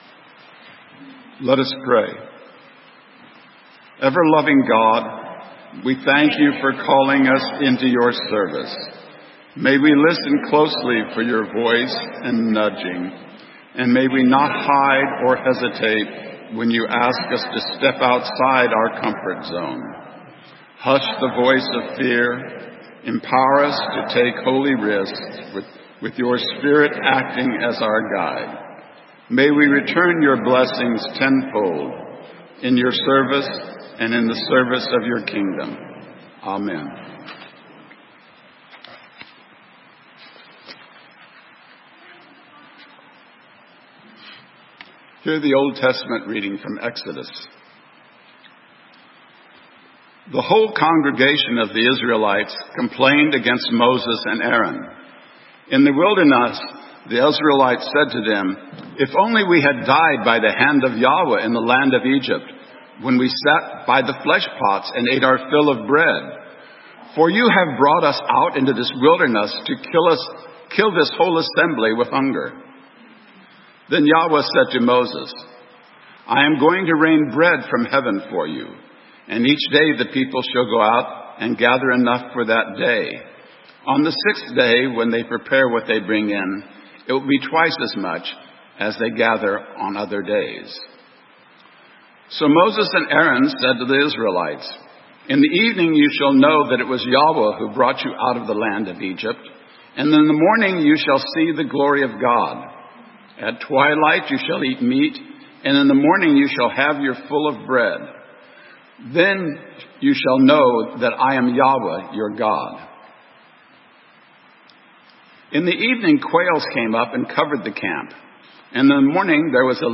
Sermon:Rich in mind, rich in service - St. Matthew's UMC